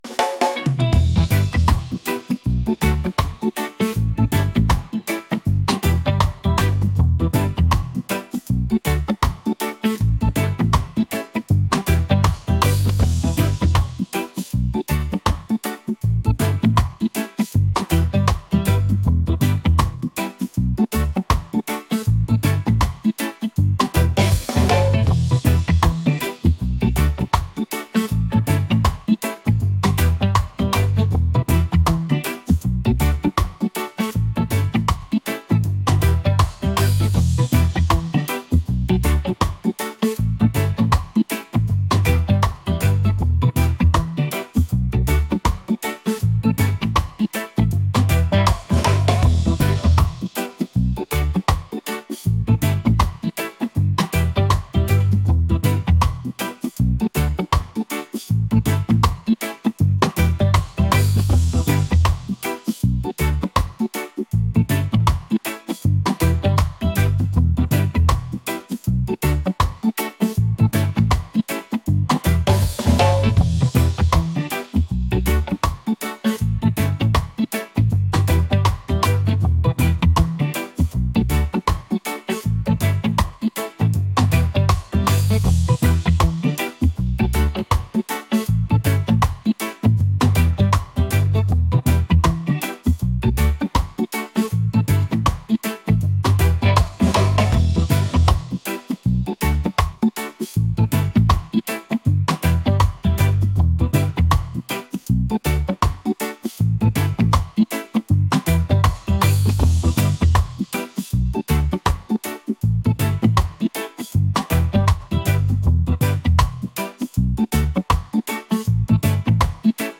reggae | upbeat | catchy